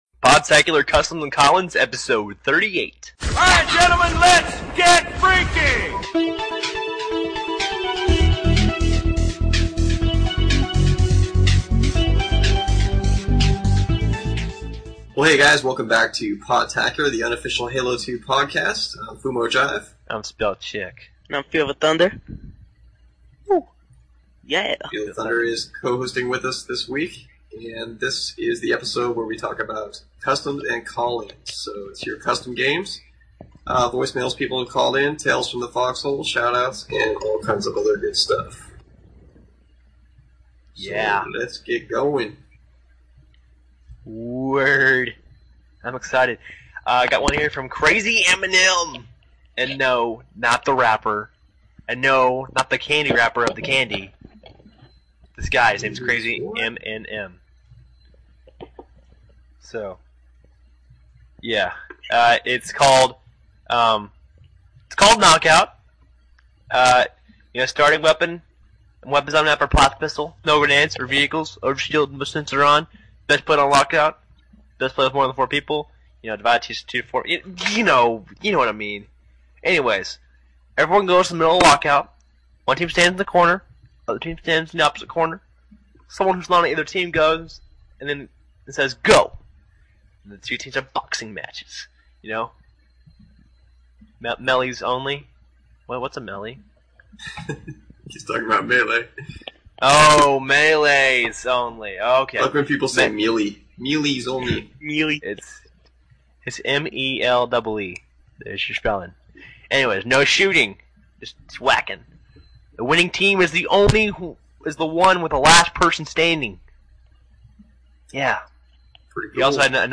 This show we talk about some cool custom games people sent in, tales from the fox hole, weird gamertags, voice mails, shoutouts and more! With guest host